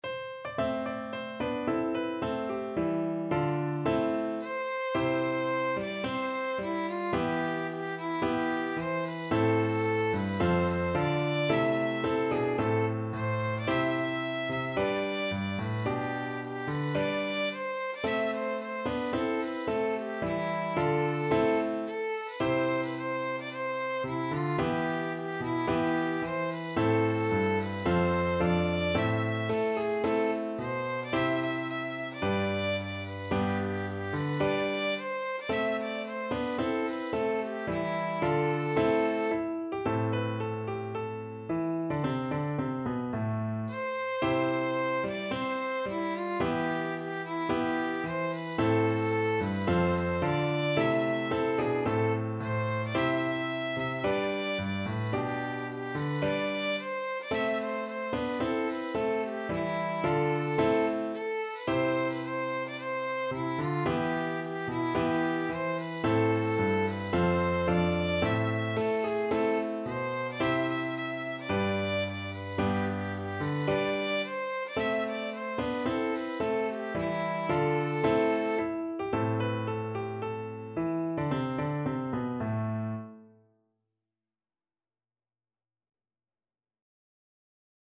Violin version
~ = 110 Allegro (View more music marked Allegro)
Traditional (View more Traditional Violin Music)